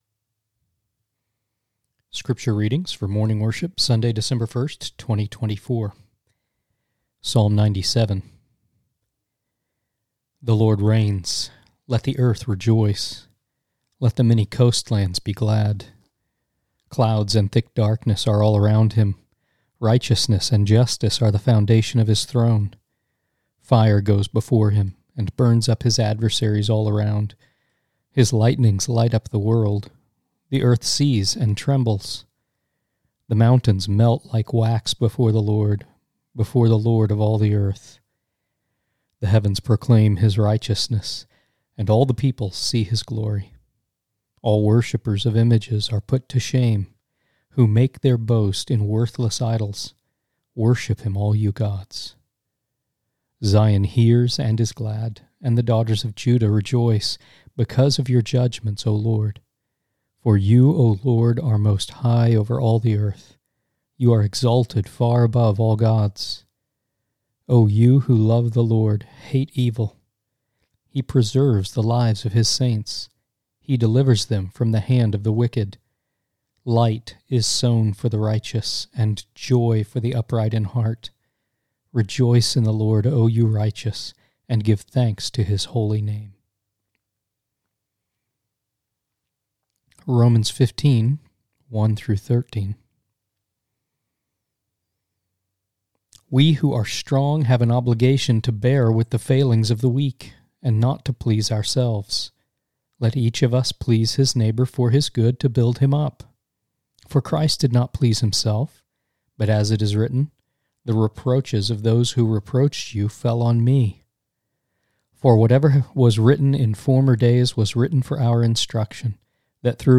1 Scripture Readings, Morning Worship | Sunday, December 1, 2024